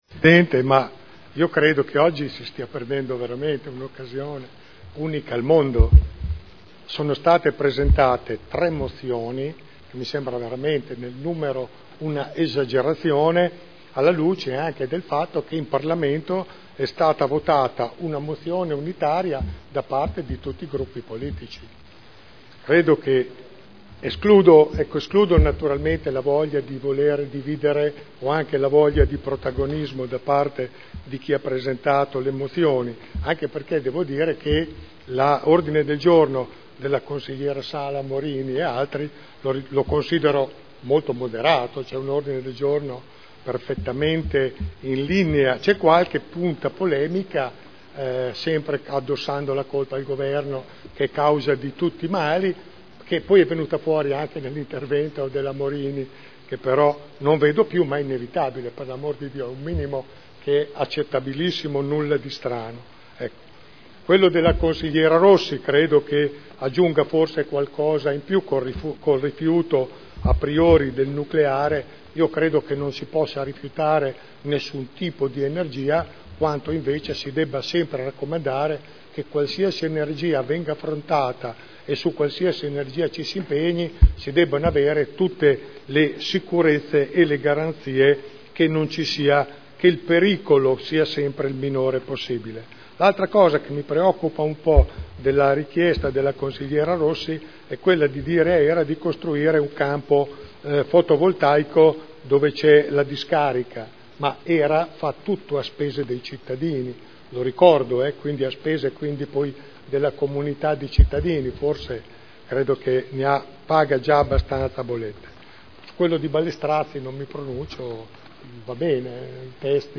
Audio Consiglio Comunale / Consigliatura 2009-14 / Archivio 2011 / aprile / Seduta del 4 aprile 2011